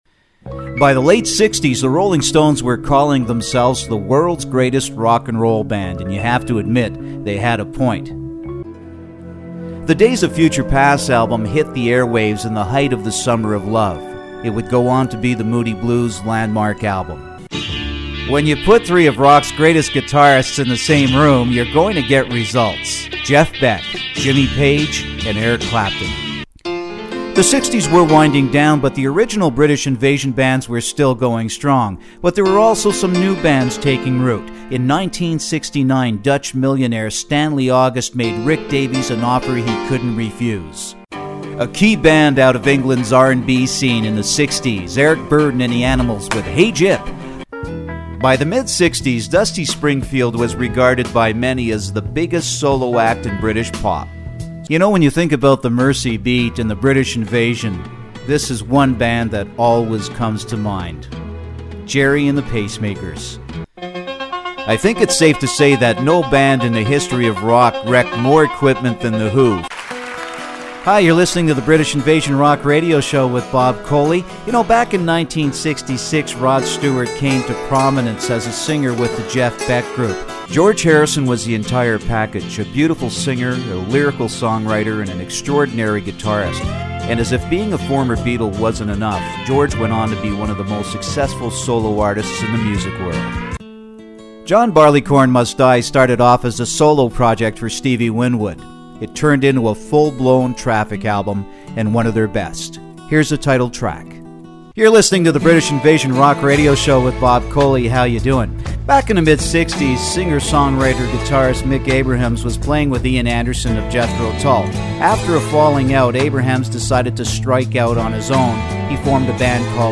Sprecher us-amerikanisch. 25 Jahre Radioarbeit: Nachrichten, Sport etc. -
middle west
Sprechprobe: Sonstiges (Muttersprache):